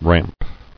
[ramp]